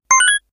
powerUp7.ogg